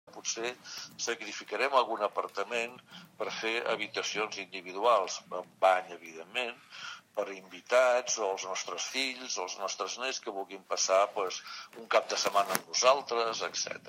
tal i com ha explicat a Ràdio Capital un dels membres del projecte